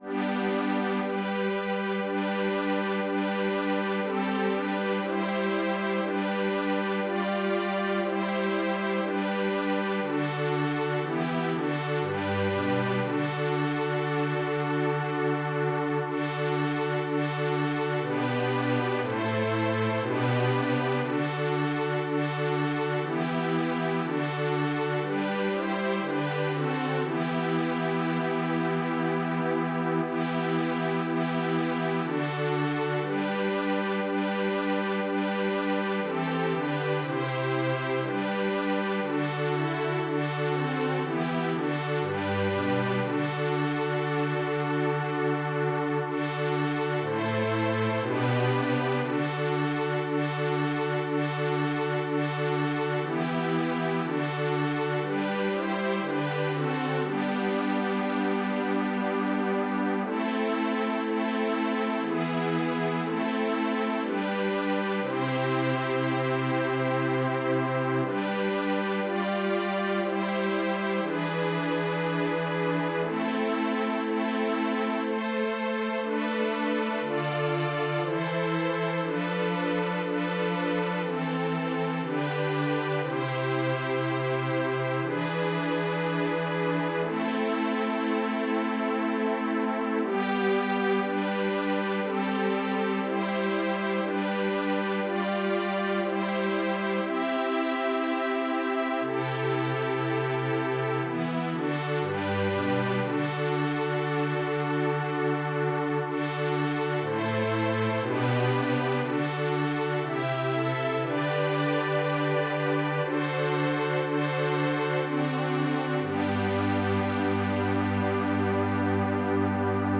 Задостойник Рождества Христова в стихотворном переложении для смешанного хора на русском языке: перевод Архиепископа Тульчинского и Брацлавского Ионафана (Елецких),